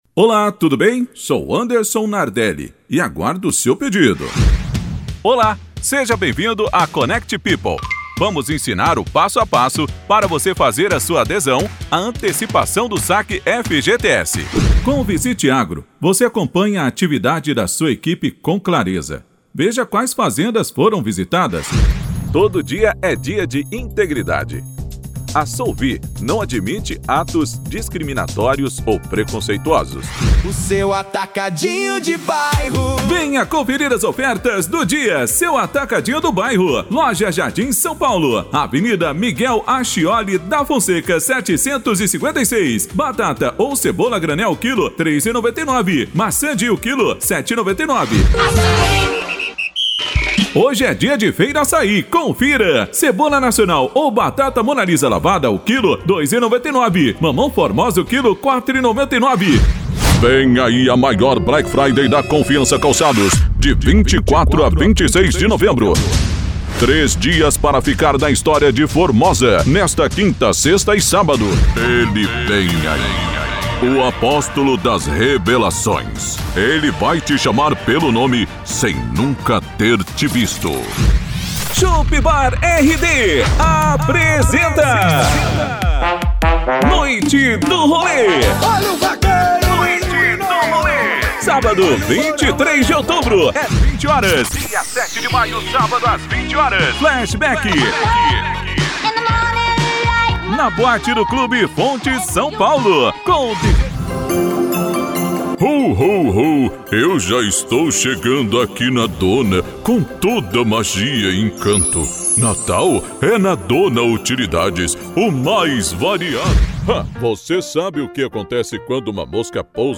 Spot Comercial
Vinhetas
Impacto
Animada
Caricata